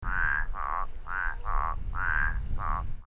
Great Basin Spadefoot - Spea intermontana